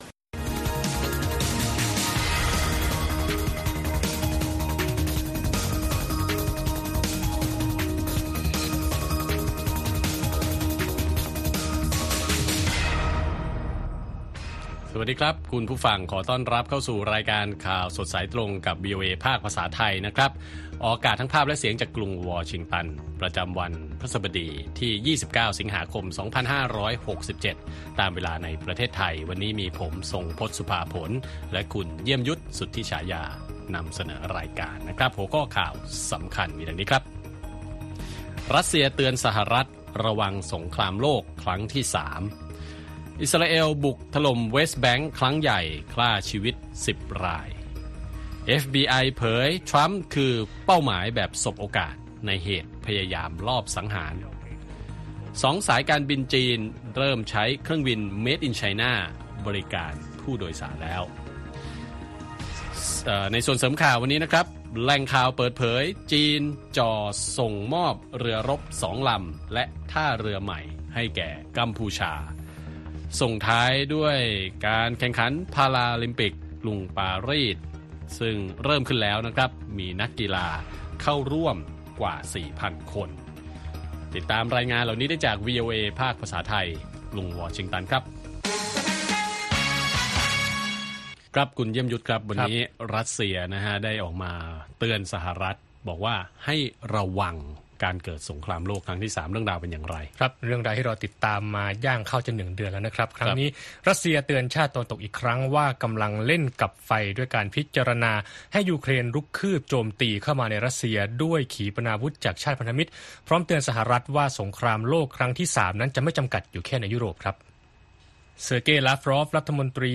ข่าวสดสายตรงจากวีโอเอไทย วันพฤหัสบดี ที่ 29 ส.ค. 2567